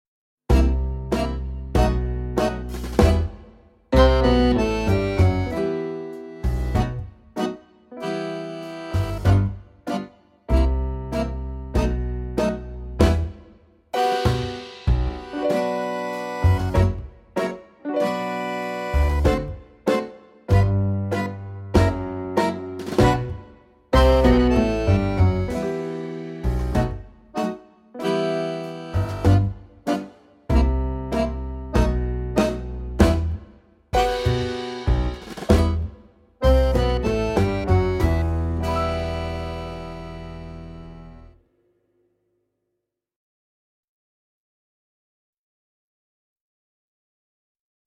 VS Strictly Tango (backing track)